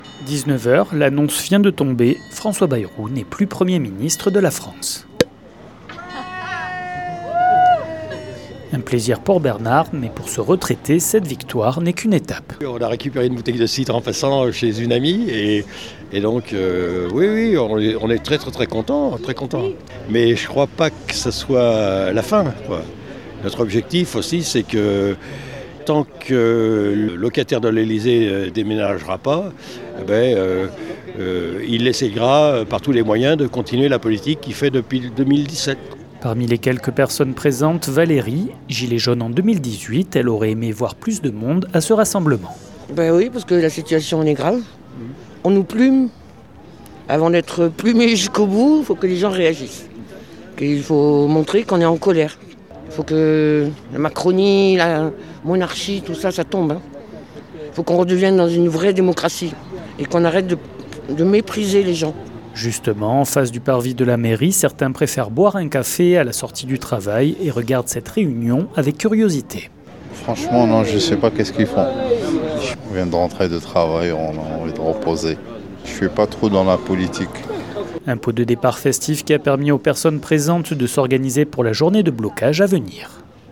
Une sorte de répétition générale avait lieu lundi soir avec des pots de départ organisés sur tout le territoire pour fêter la chute du gouvernement Bayrou. A Mende, une trentaine de personnes s’étaient réunies devant la mairie. Un moment festif pour se rassembler et organiser ce 10 septembre. 48FM s’est rendu sur place.
Reportage